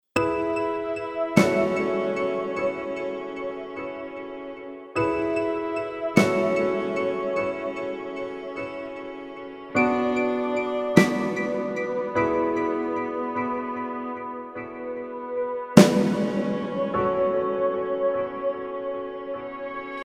رینگتون موبایل احساسی و بی کلام